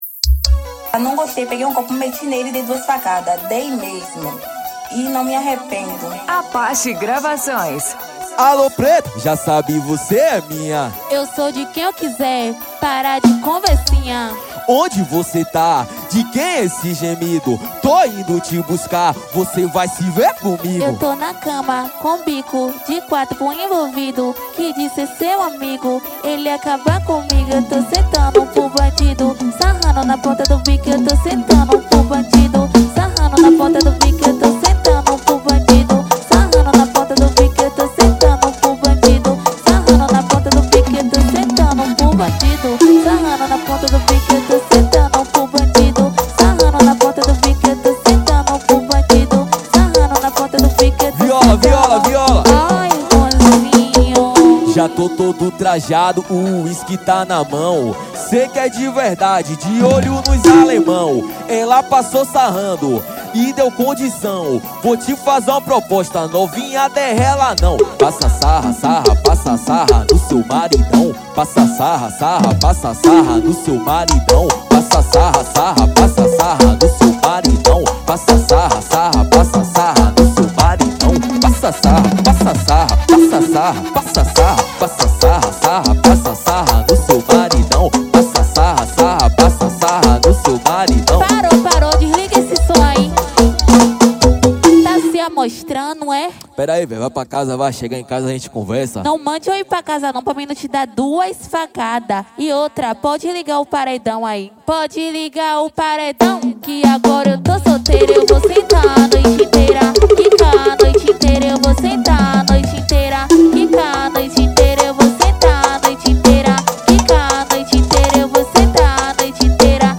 2024-11-10 22:13:59 Gênero: Axé Views